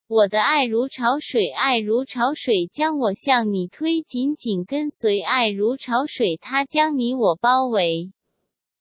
將Google小姐語音下載成 MP3音訊檔 教學